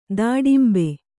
♪ dāḍimbe